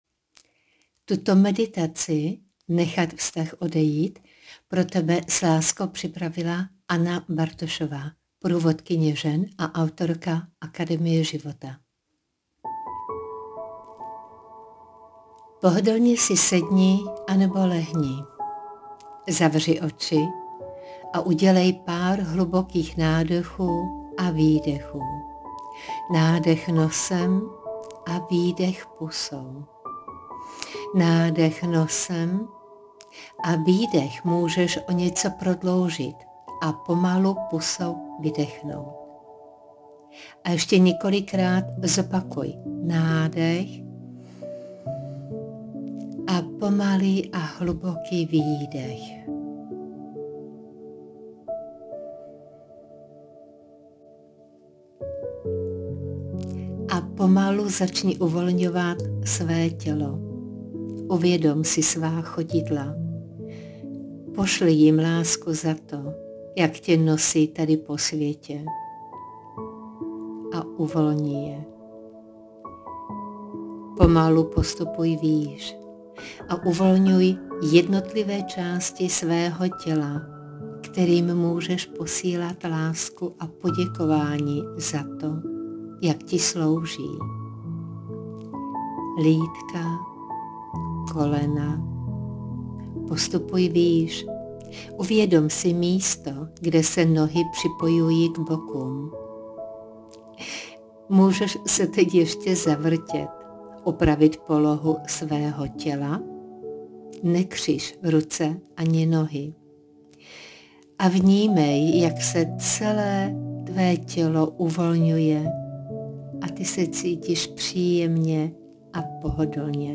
Nechat vztah odejít vedená meditace Soubory ke stažení: Nechat vztah odejít - v mp3 PŘEJI, AŤ JE TI TATO MEDITACE PROSPĚŠNÁ V TVÉ SOUČASNÉ SITUACI.
Nechat-vztah-odejit-meditace.mp3